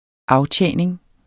Udtale [ ˈɑwˌtjεˀneŋ ]